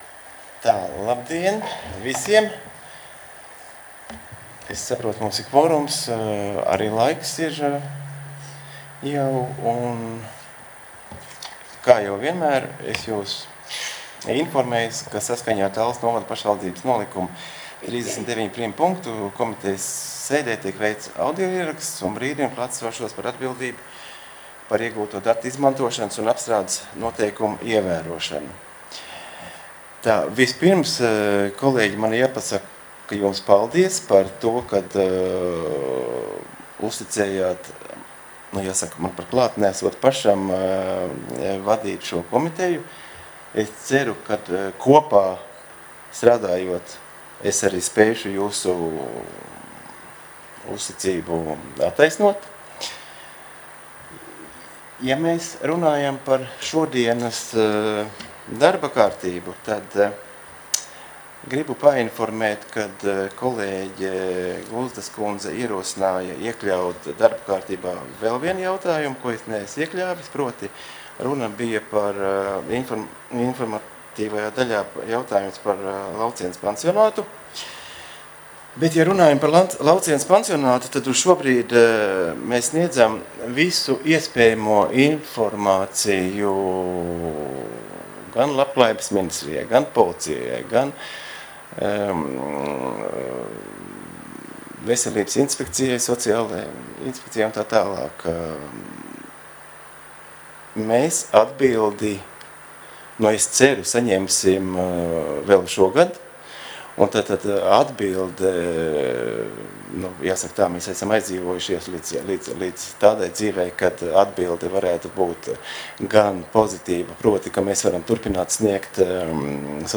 19. Sociālo un veselības jautājumu komitejas sēdes protokols